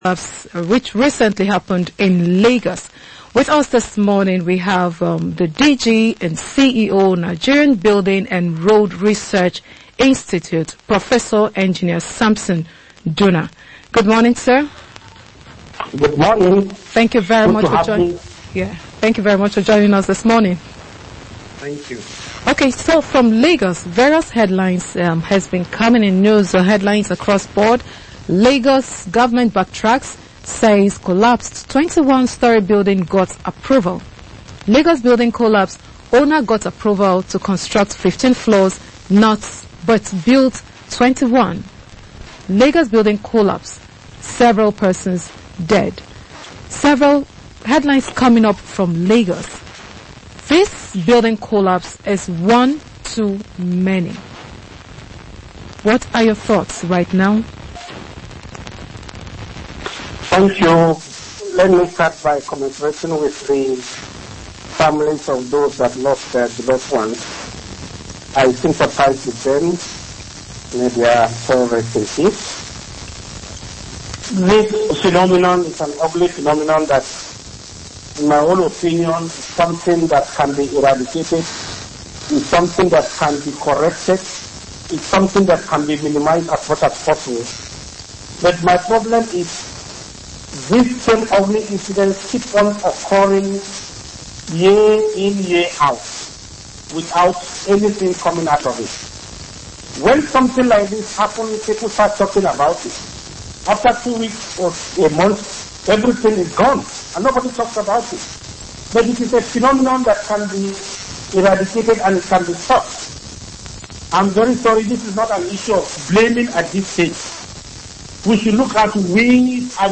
DG NBRRI Speaks on Ikoyi Building Collapse